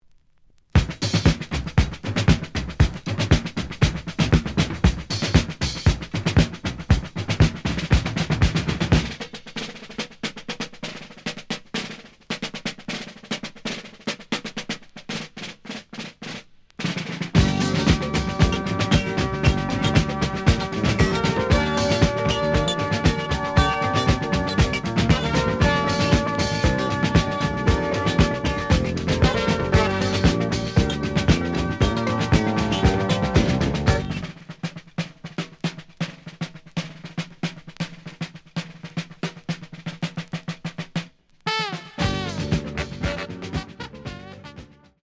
The audio sample is recorded from the actual item.
●Format: 7 inch